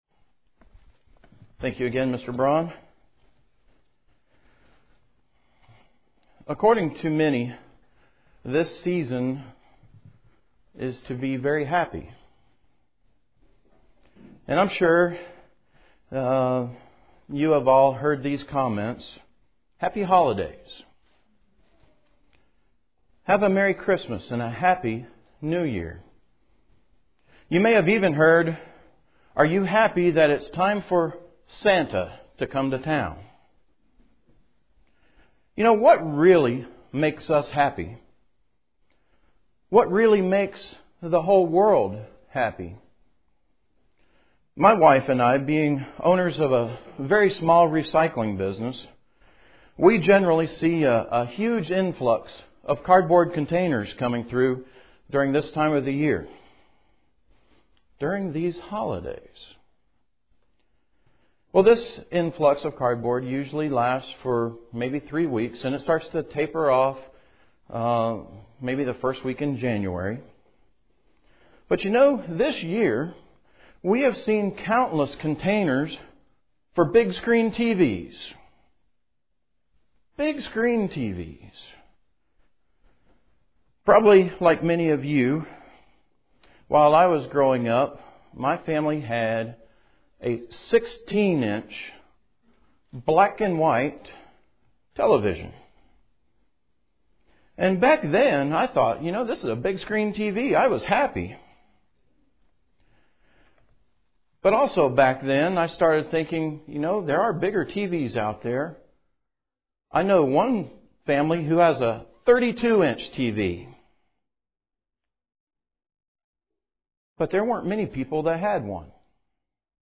UCG Sermon Studying the bible?
Given in Charlotte, NC